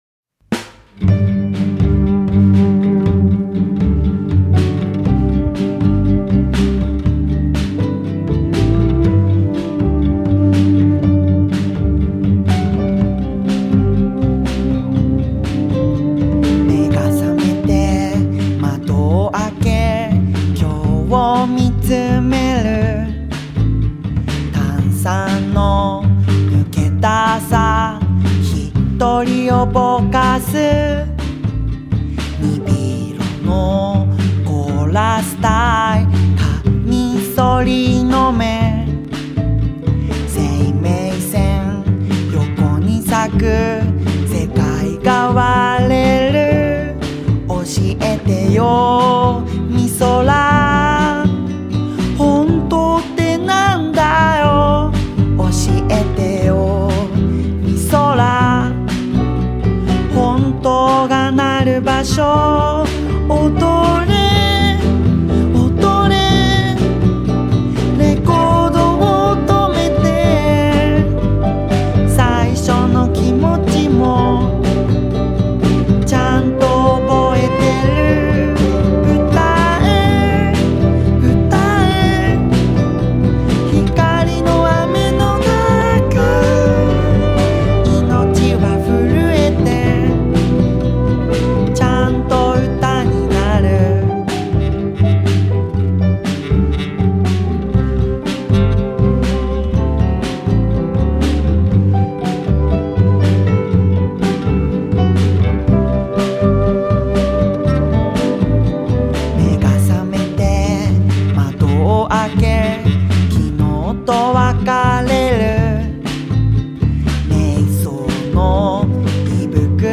歪な形をした危ういムードと甘くメロウなメロディが同居した、素晴らしい歌のアルバムです！